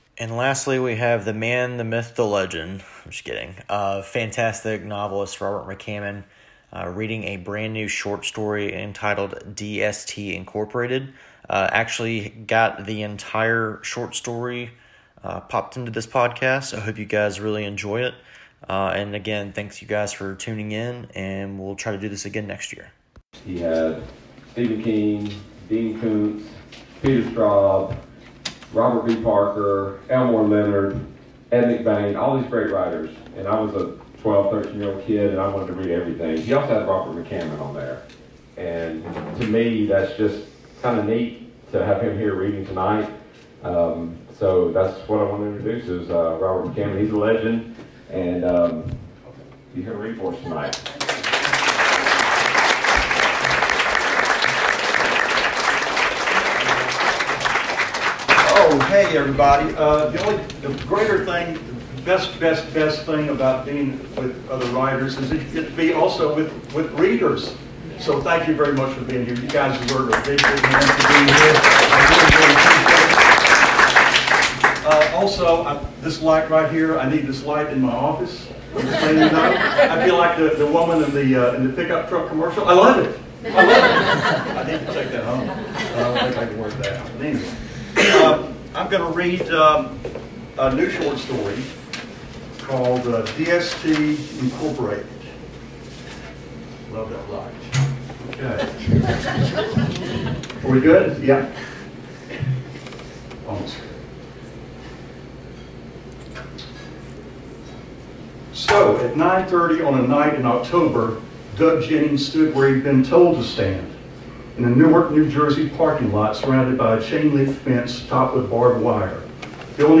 Readings – Robert McCammon